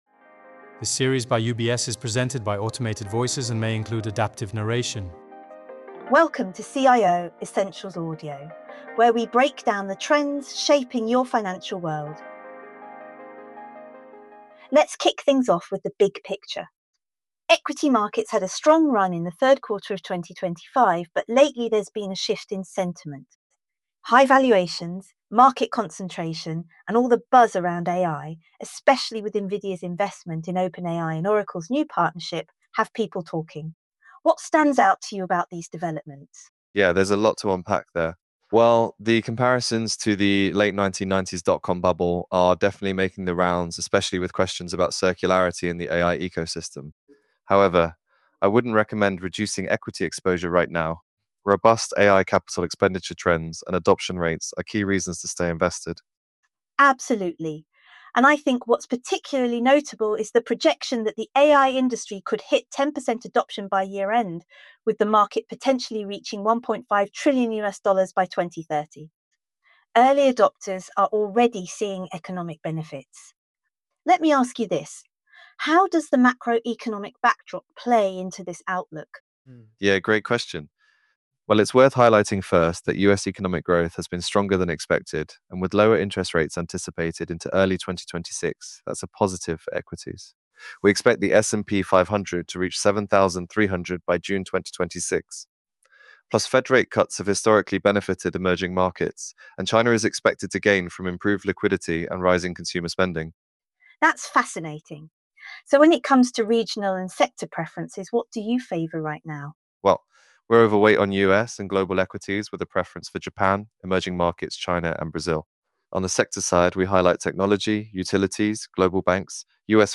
CIO Essentials audio versions are generated using AI technology.
However, we believe there is still room for equity markets to advance, which is why we have upgraded global equities for Attractive. Presented by automated voices.